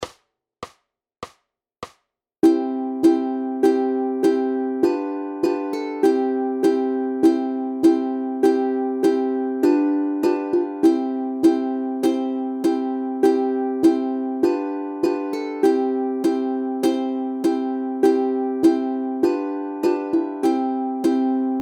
Aranžmá Noty, tabulatury na ukulele
Hudební žánr Vánoční písně, koledy